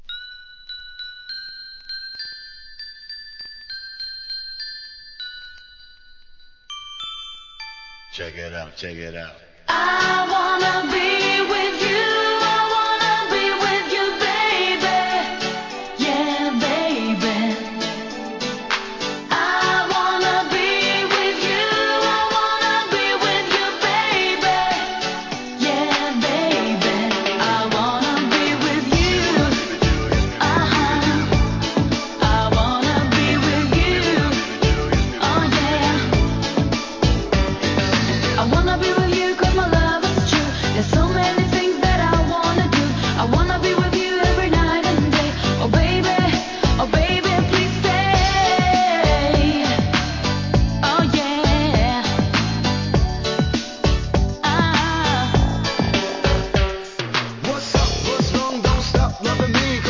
HIP HOP/R&B
レゲエ調のギターリフが気持ちイ〜トラックにポップスな歌声で大ヒット!!